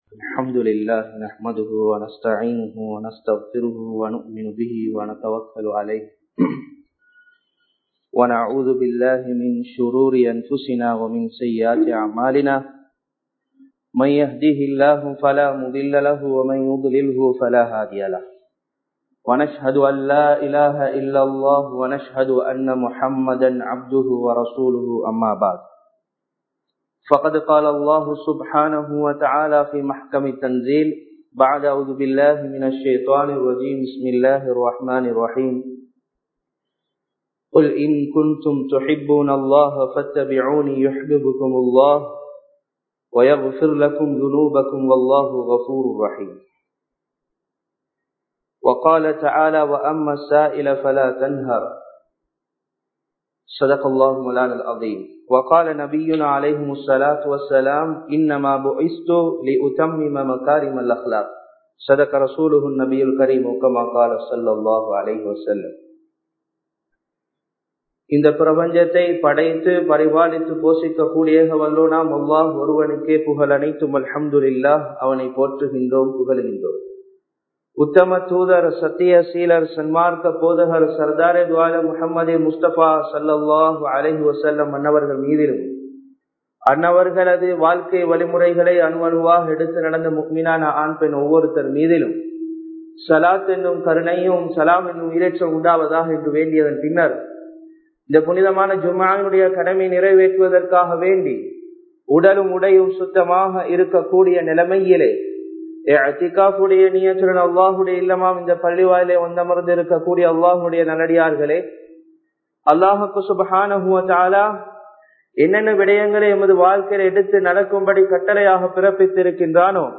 நபி(ஸல்)அவர்களின் கொடைத் தன்மை | Audio Bayans | All Ceylon Muslim Youth Community | Addalaichenai
Sabeelur Rashad Jumua Masjidh